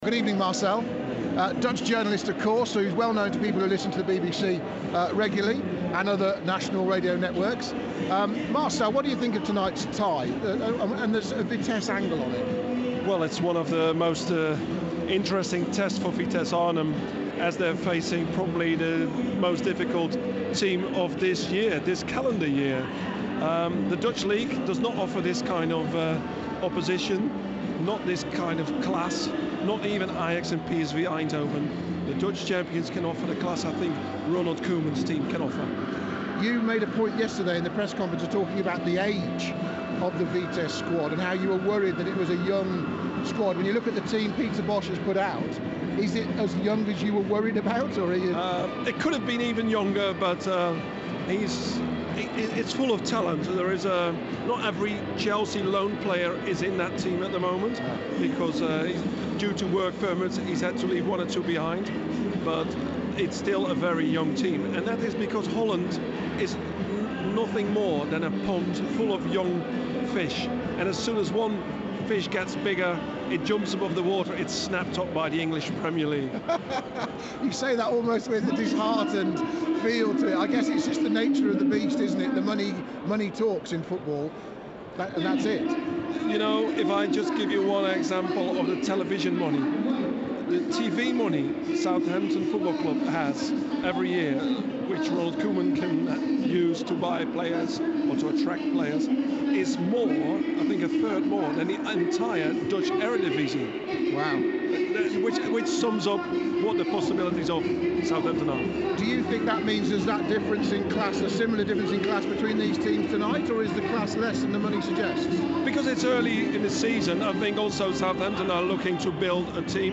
Dutch reporter